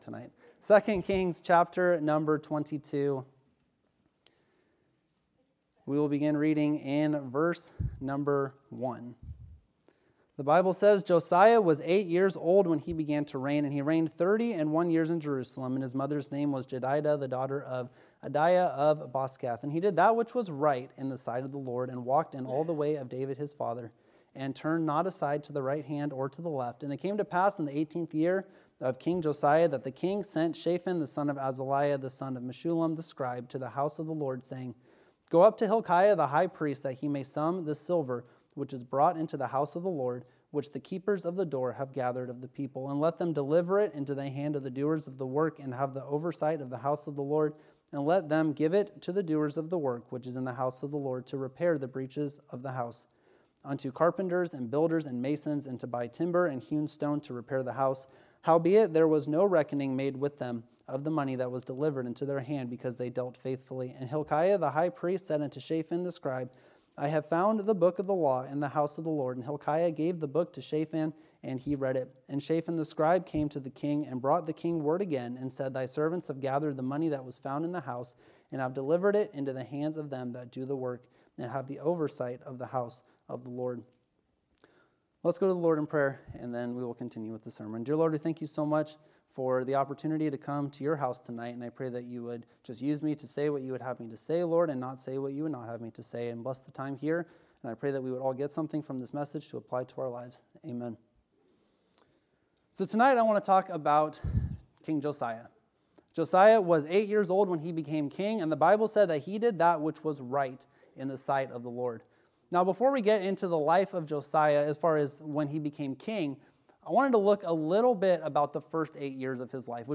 Wednesday Night Bible Study – Shasta Baptist Church